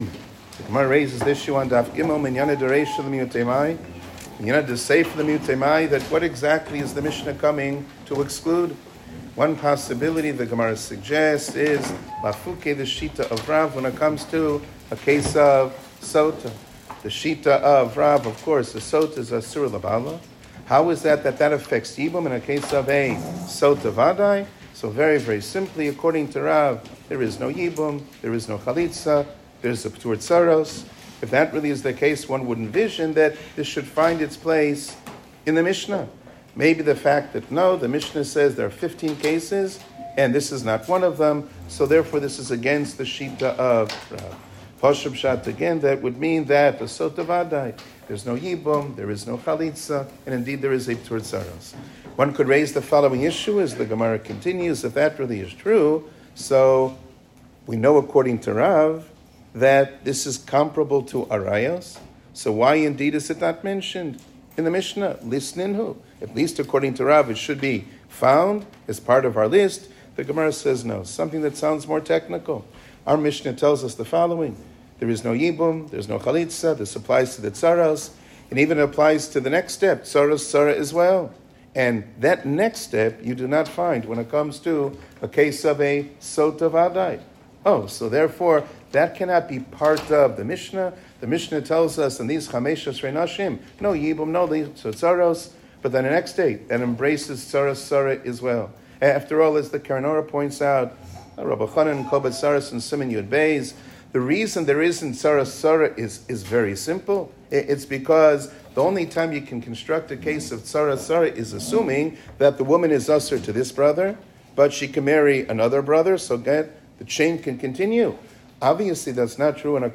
שיעור כללי - צרת סוטה